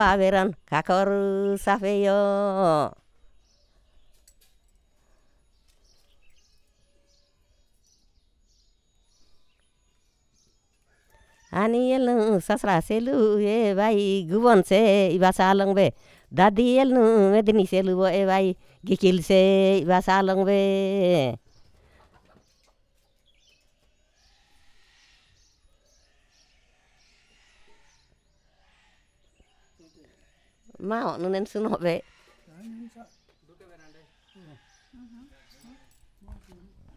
Perfomance of folk song